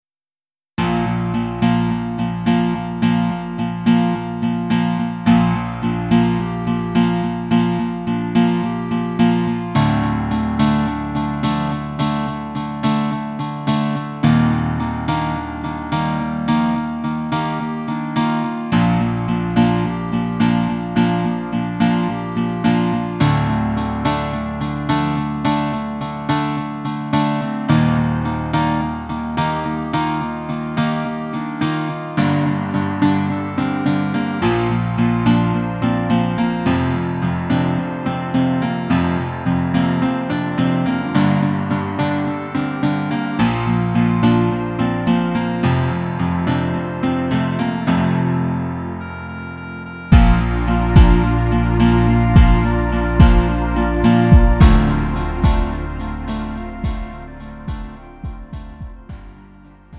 음정 -1키 3:23
장르 구분 Lite MR